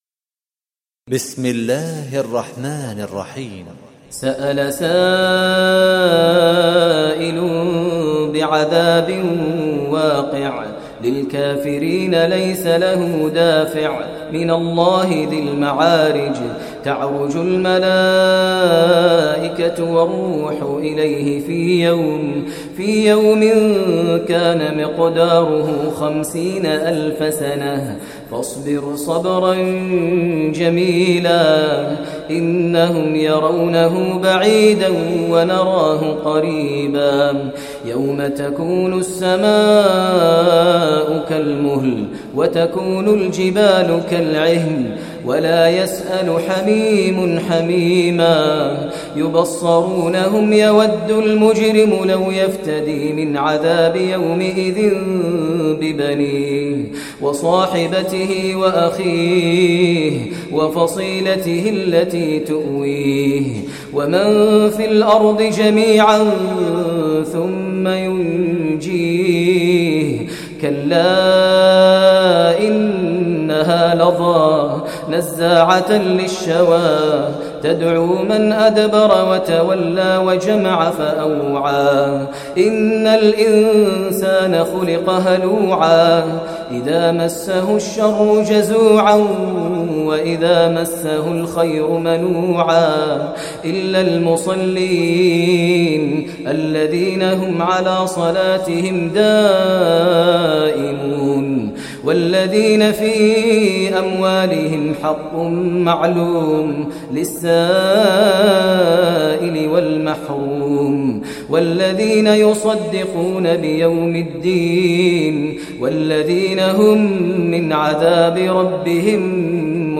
Surah Al Maarij Recitation by Maher al Mueaqly
Surah Al Maarij, listen online tilawat / recitation in Arabic in the voice of Sheikh Maher al Mueaqly.